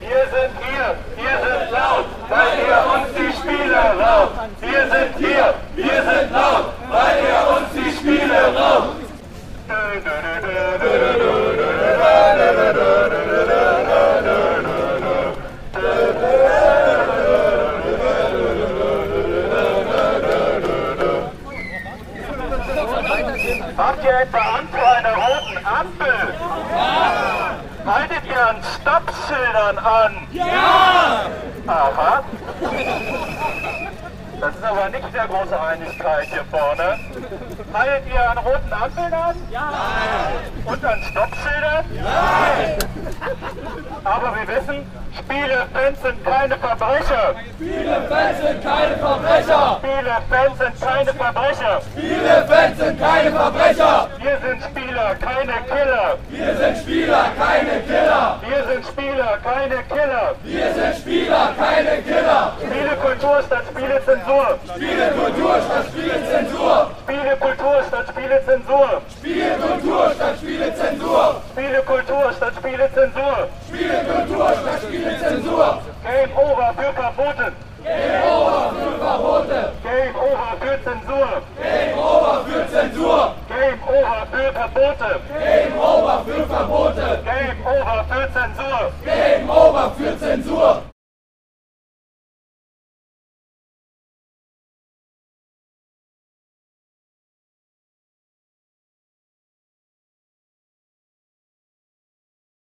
"Game Over für Verbote, Game Over für Zensur!" - Wer heute, am 25. Juli, durch Berlin Mitte spaziert ist, hat einen Demozug der ungewöhnlichen Art zu sehen und hören bekommen.
In einem Demozug marschieren und lauthals markige Sprüche skandieren ist für die heutigen Teilnehmerinnen und Teilnehmer ungewohnt.
Und so klingt die Gamer-Demo: